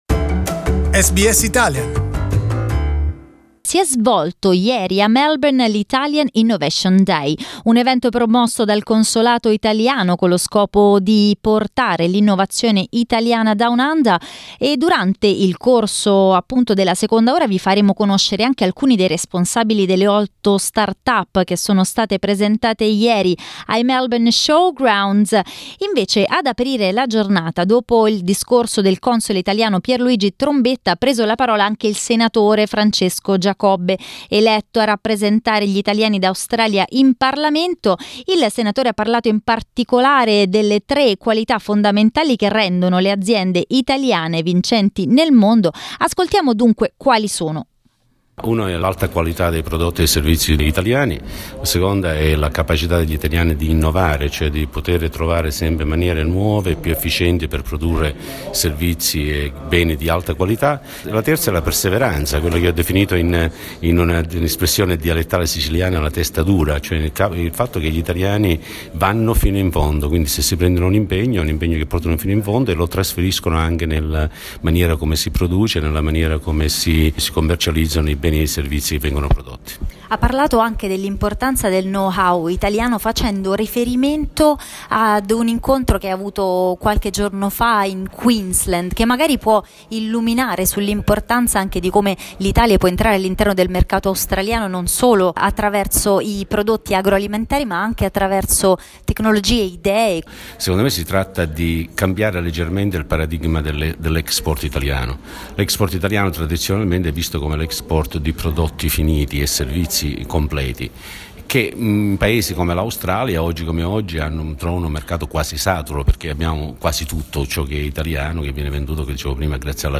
SBS Italian spoke with some of the young Italian entrepreneurs present on the day.